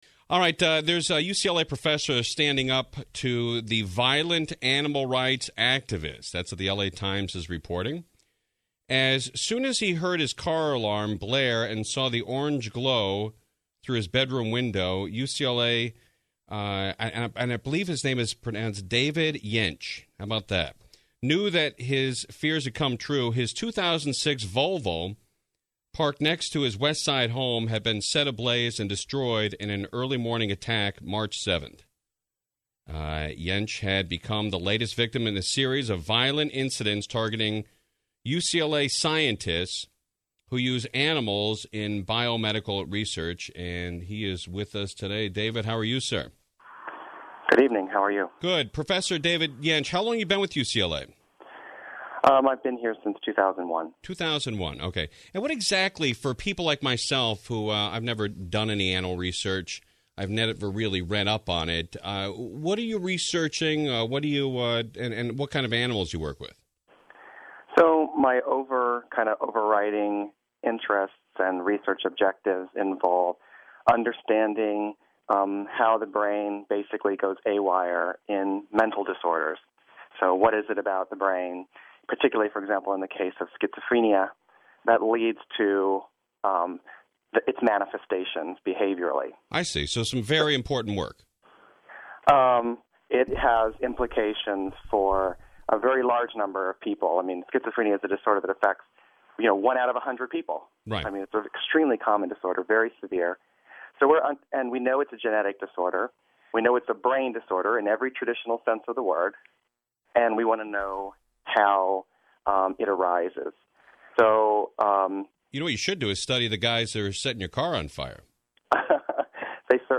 now the radios are fighting for interviews